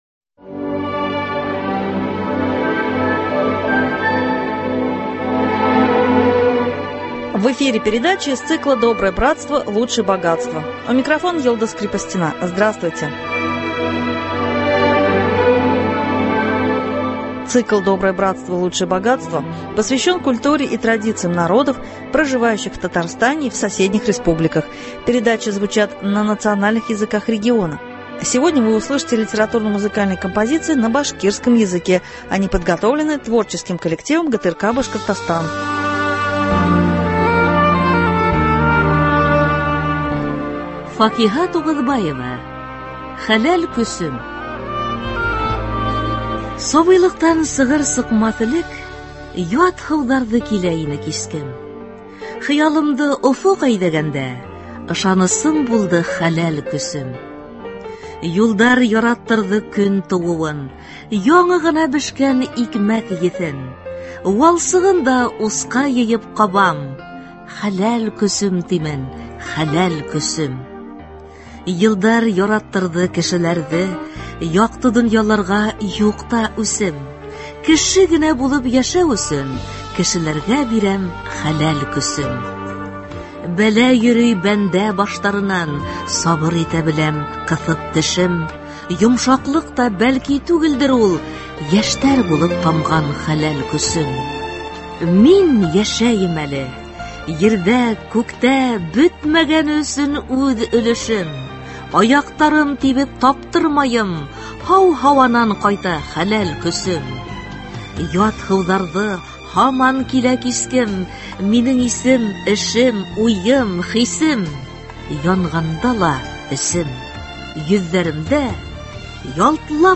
Музыкально- литературная композиция о родном крае ( на башкирском языке).